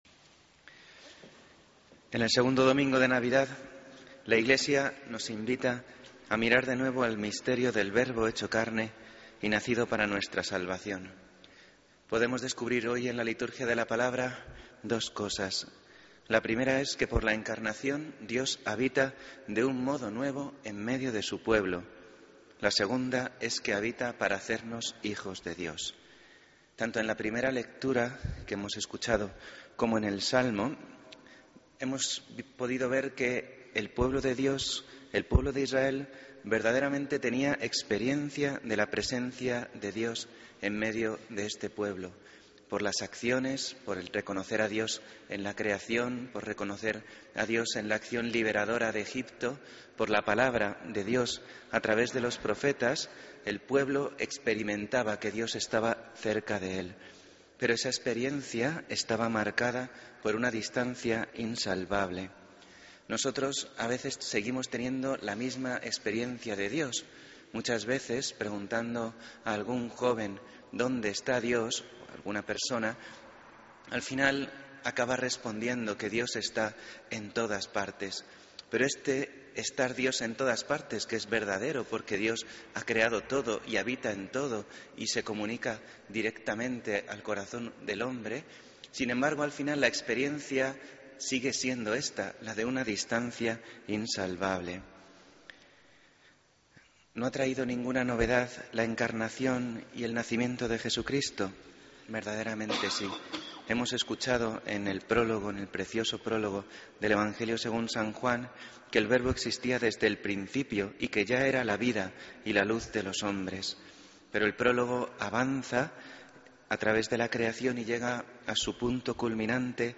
Homilía del 5 de Enero de 2014